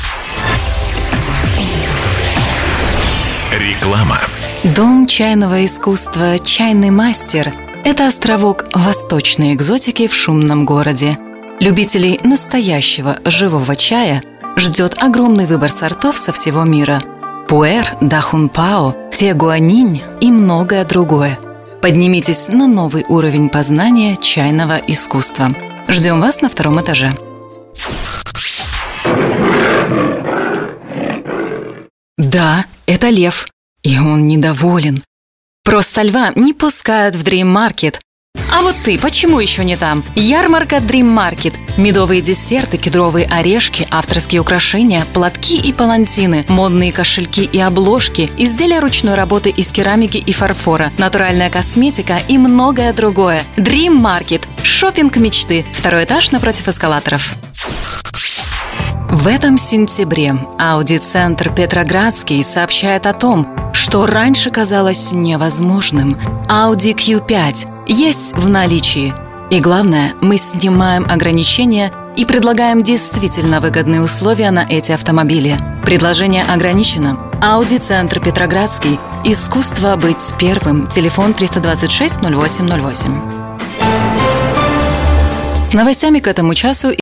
Пример звучания голоса
Жен, Рекламный ролик/Средний
Конденсаторный микрофон Behringer B-1, звуковая карта Audient Evo 4